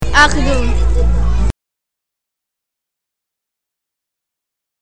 uitspraak